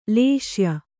♪ lēśya